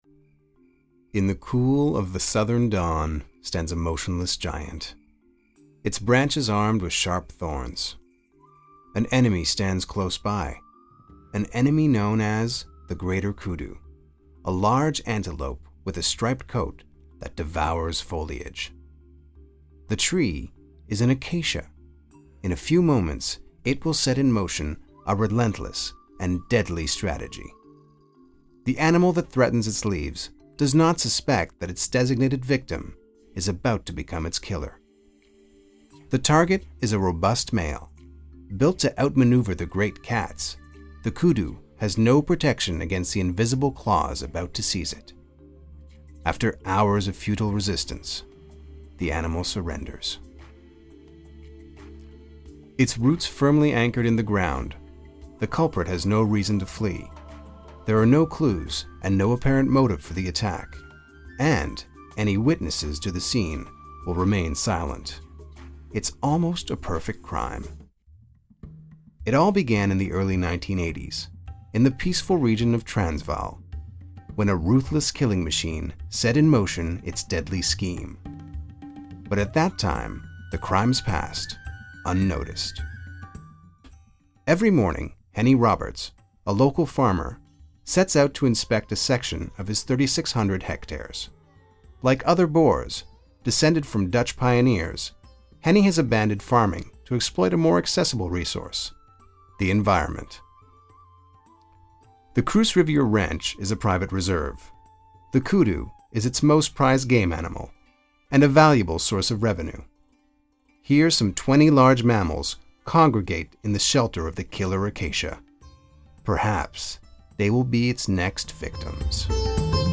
Narration - EN